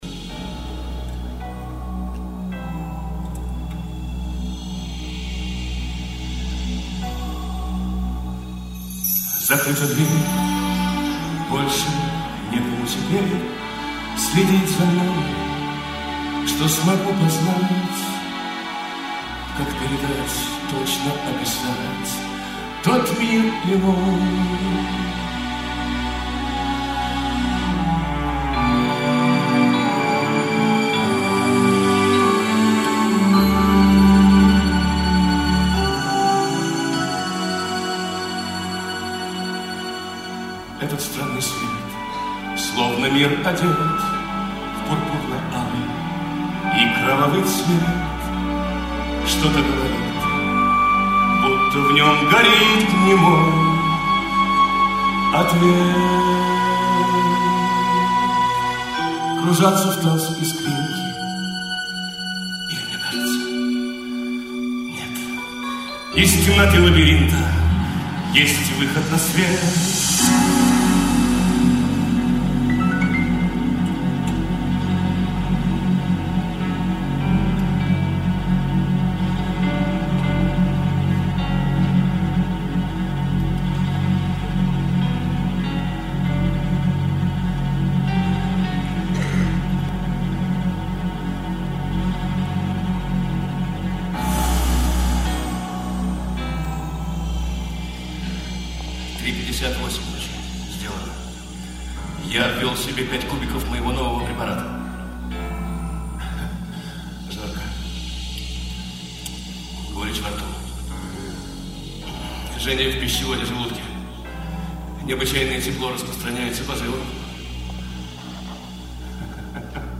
Музыкальная драма в 2-х частях
Аудио запись со спектакля от 26.05.2005 г*.